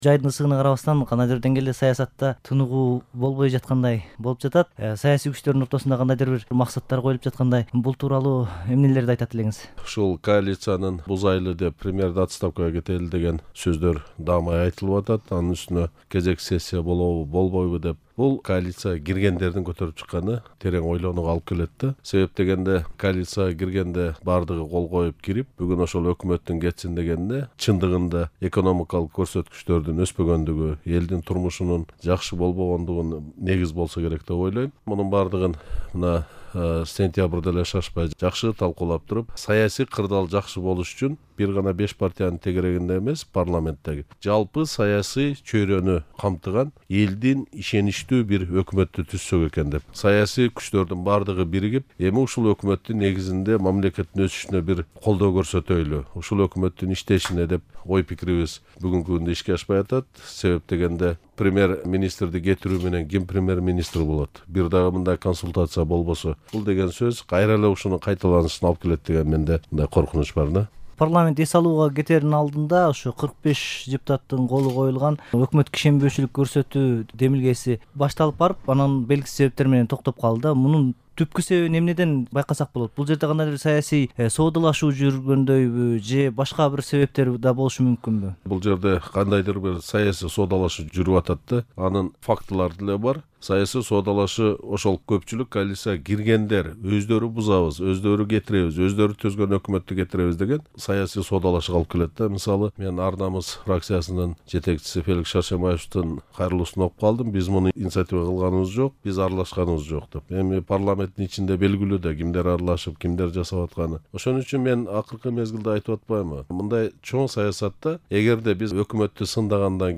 Келдибеков менен маек